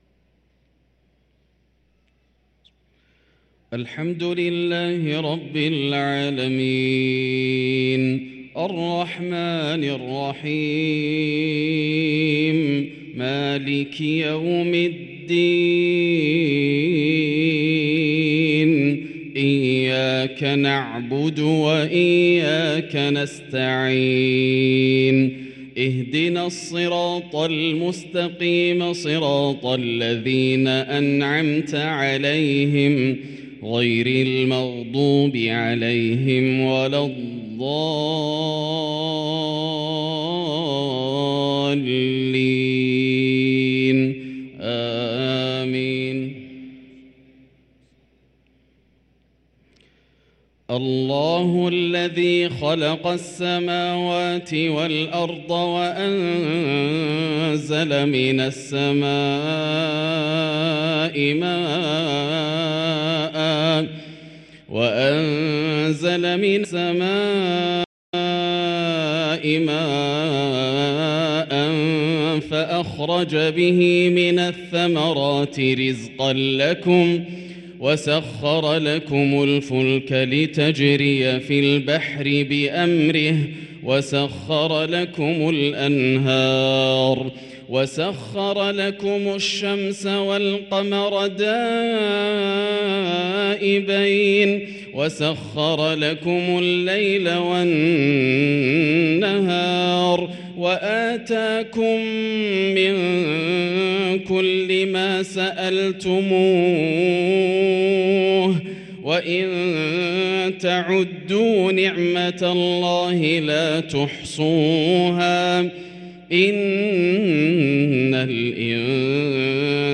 صلاة العشاء للقارئ ياسر الدوسري 23 شعبان 1444 هـ
تِلَاوَات الْحَرَمَيْن .